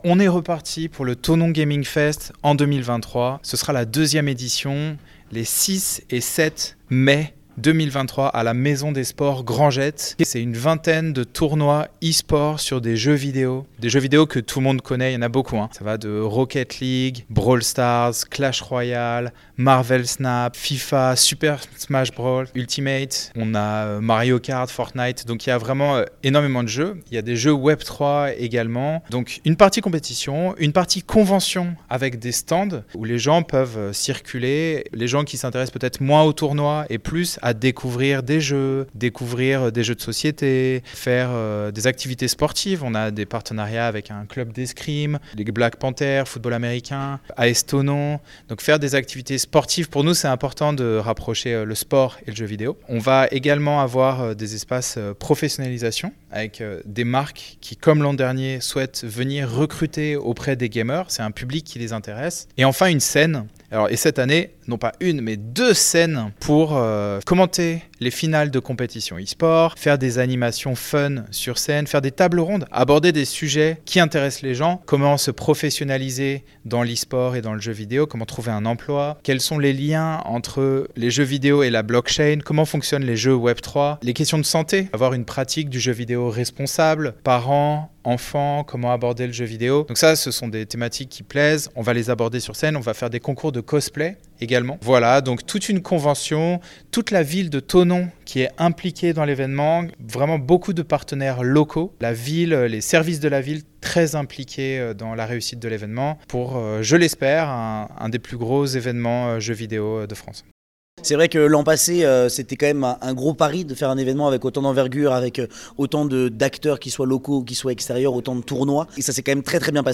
La deuxième édition du Thonon Gaming Fest se déroulera dans la capitale du Chablais les 6 et 7 mai prochains (interviews)